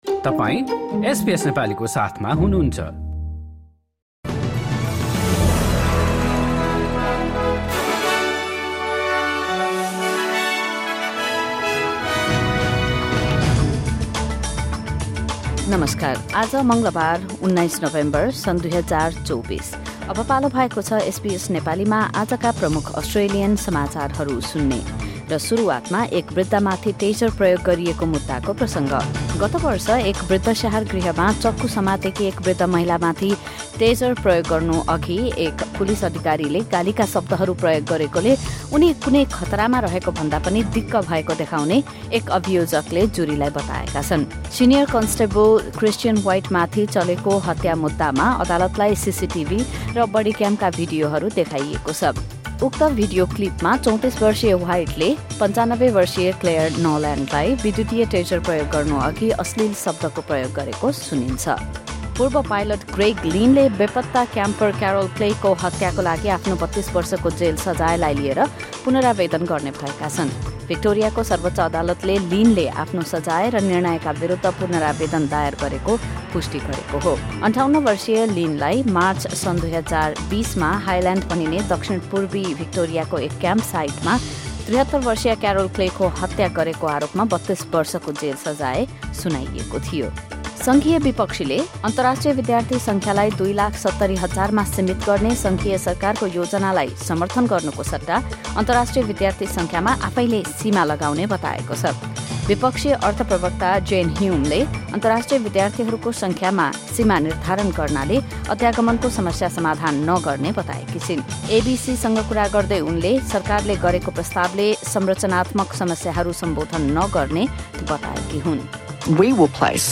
SBS Nepali Australian News Headlines: Tuesday, 19 November 2024